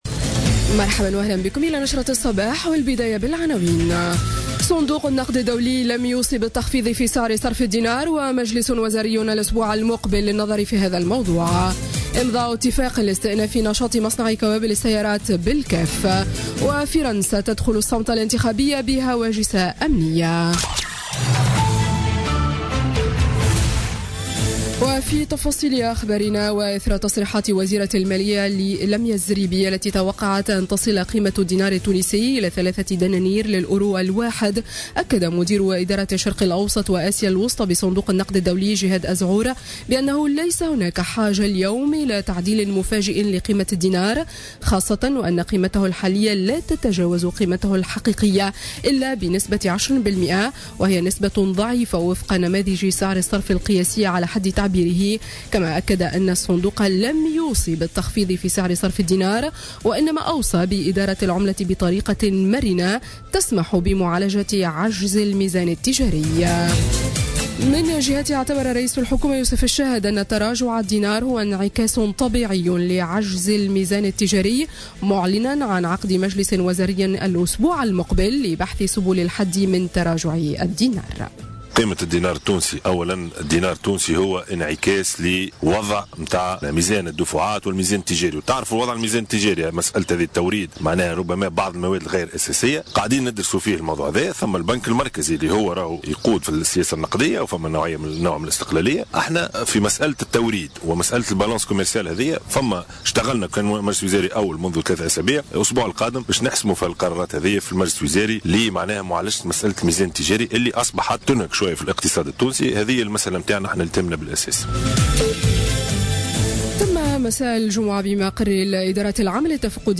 نشرة أخبار السابعة صباحا ليوم السبت 22 أفريل 2017